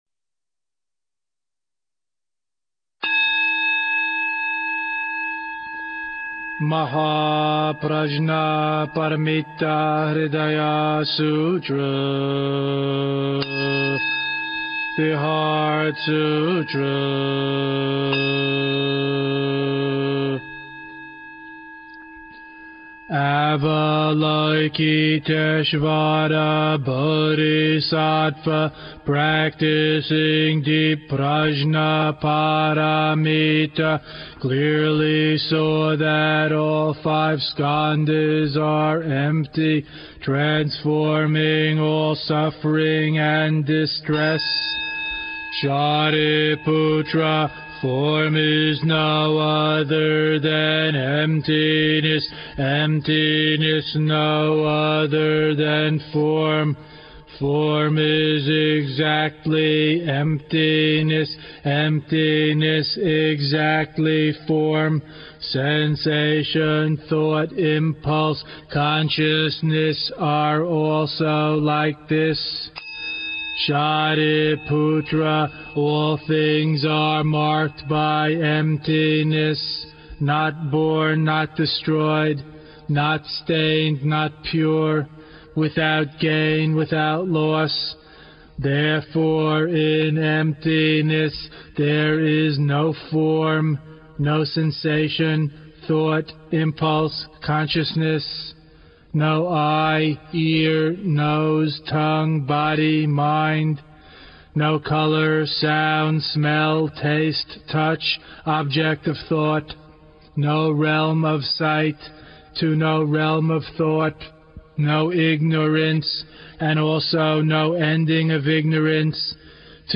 chanting